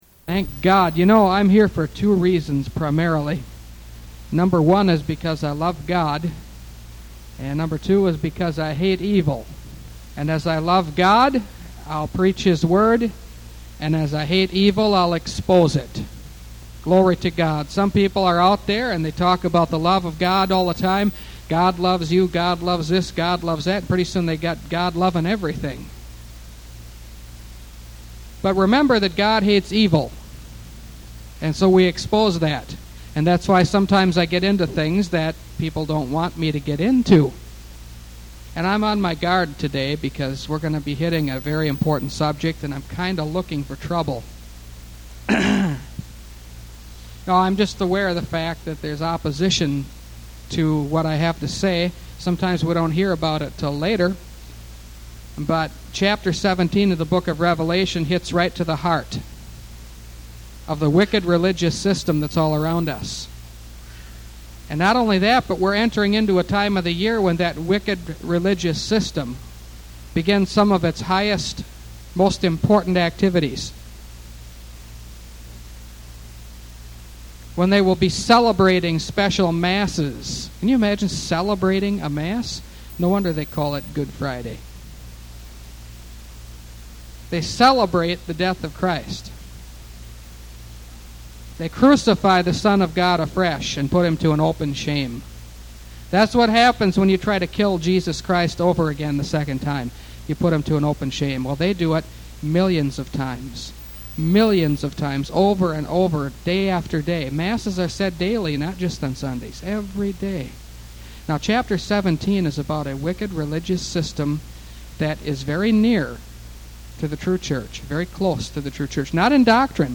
Revelation Series – Part 39 – Last Trumpet Ministries – Truth Tabernacle – Sermon Library